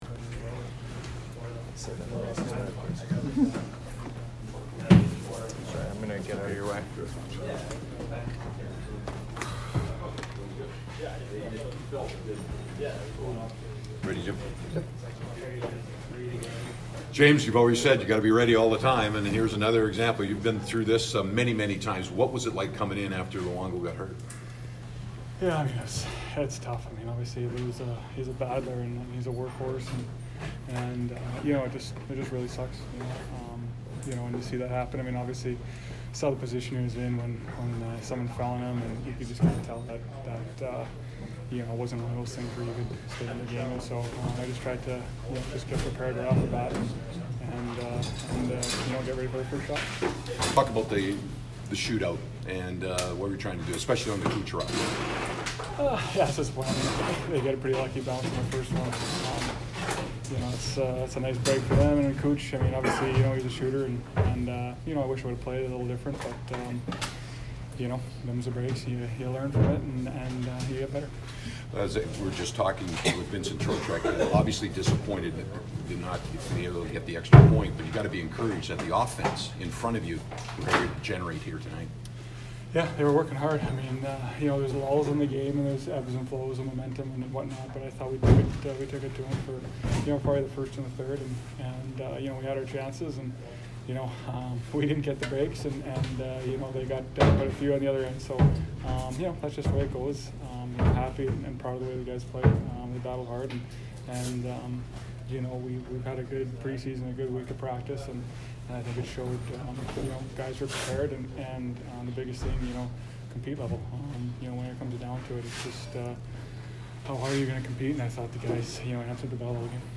James Reimer post-game 10/6